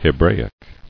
[He·bra·ic]